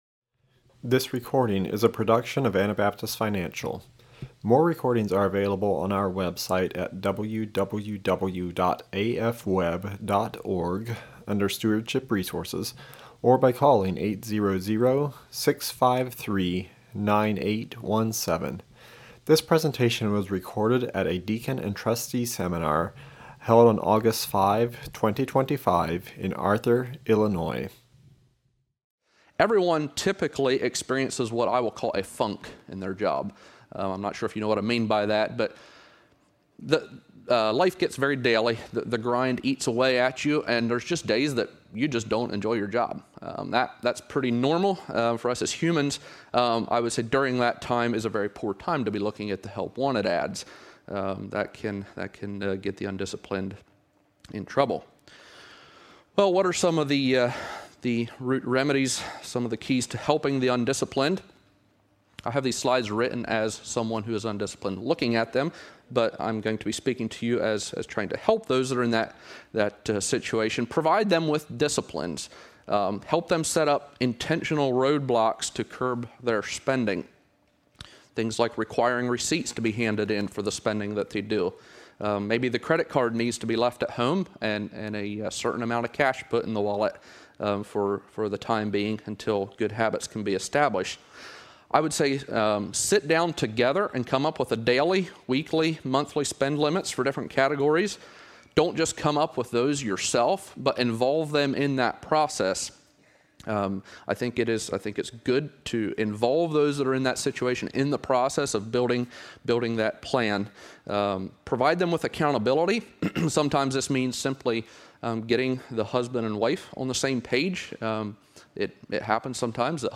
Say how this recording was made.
This is a recording from the Seminar for Deacons, Financial Advisors, and Trustees held in Arthur, IL in 2025.